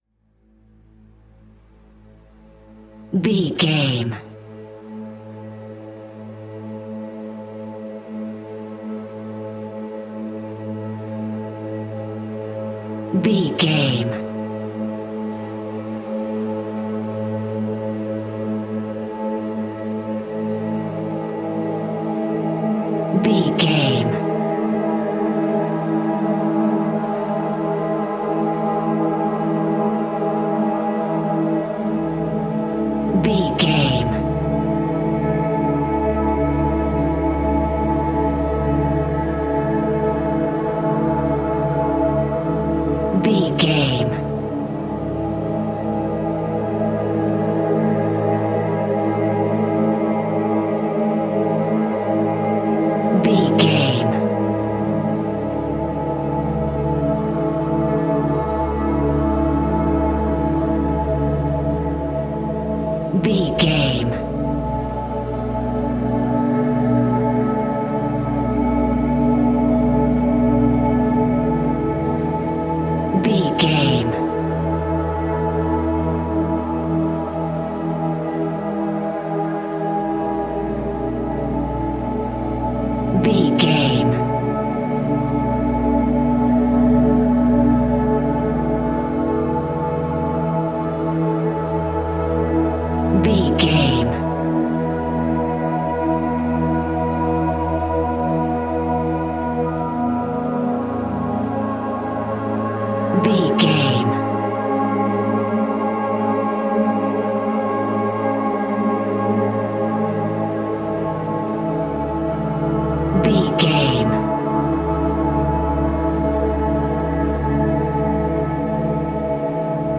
Ionian/Major
Slow
calm
ambient
atmospheric
cinematic
meditative
melancholic
dreamy
uplifting
synthesiser